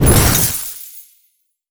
ice_blast_projectile_spell_01.wav